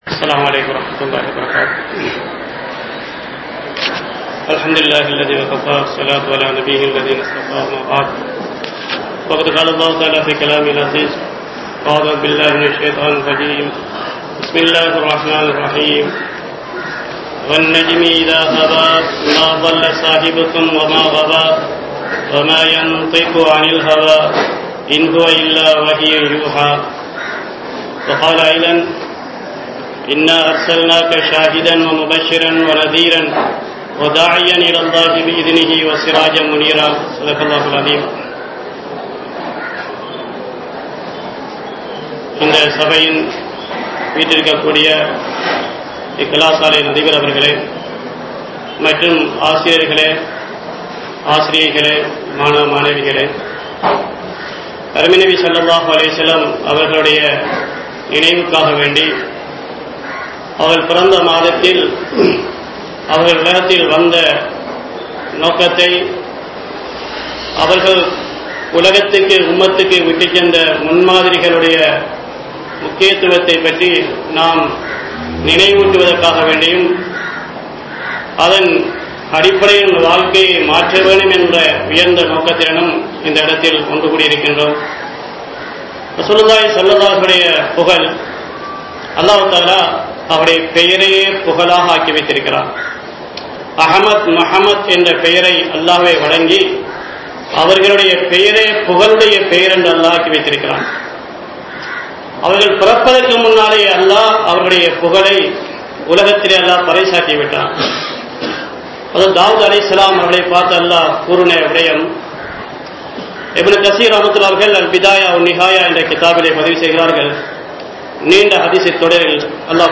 Islaathai Alikkum Indraya Sathihaararhal (இஸ்லாத்தை அழிக்கும் இன்றைய சதிகாரர்கள்) | Audio Bayans | All Ceylon Muslim Youth Community | Addalaichenai
Alighar National School